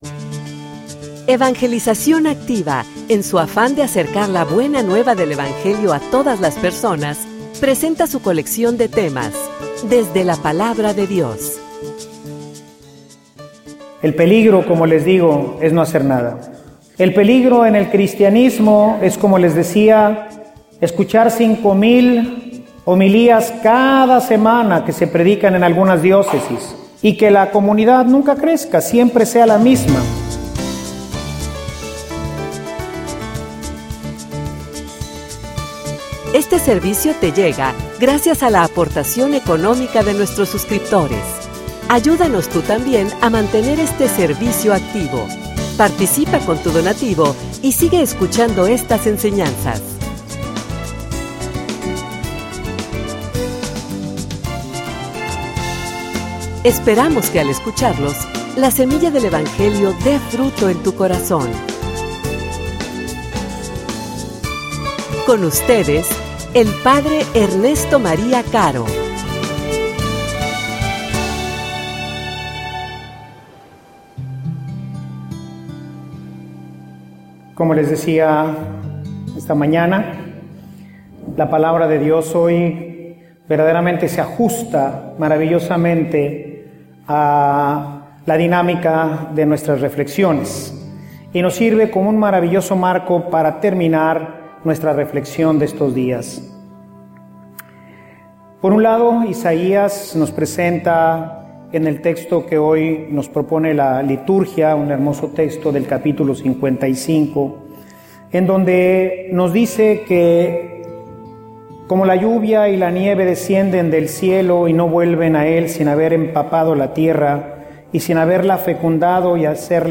homilia_Entiendes_o_solo_escuchas.mp3